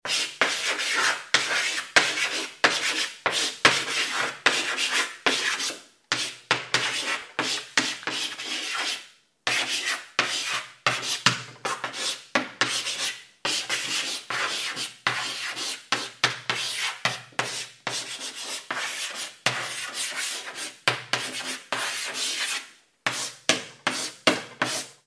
دانلود آهنگ گچ 1 از افکت صوتی اشیاء
جلوه های صوتی
برچسب: دانلود آهنگ های افکت صوتی اشیاء دانلود آلبوم صدای کشیدن گچ روی تخته سیاه از افکت صوتی اشیاء